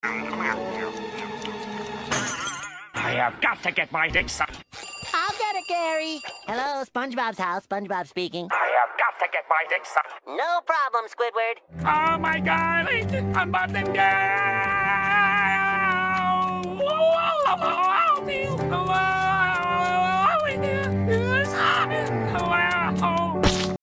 🧽 Follow for more deep fried sound effects free download